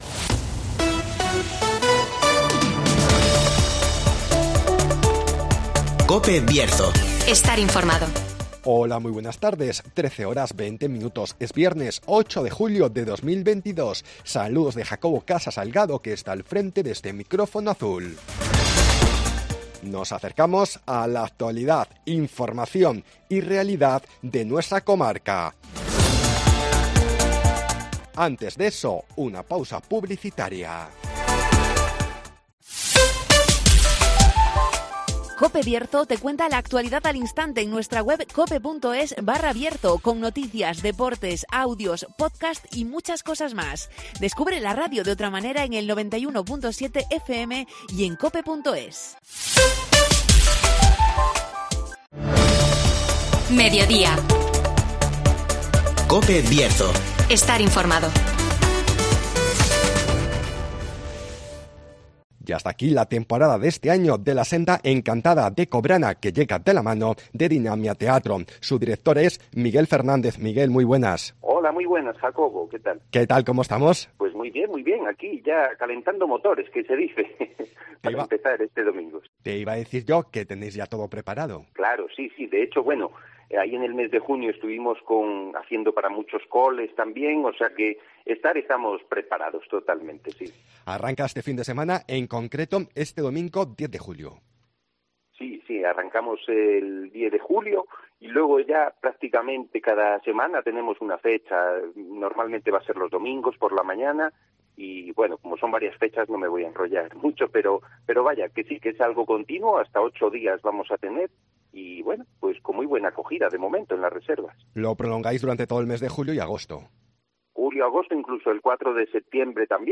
Ya está aquí la temporada de este año de la Senda Encantada de Cobrana (Entrevista